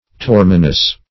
torminous - definition of torminous - synonyms, pronunciation, spelling from Free Dictionary Search Result for " torminous" : The Collaborative International Dictionary of English v.0.48: Torminous \Tor"mi*nous\, a. (Med.)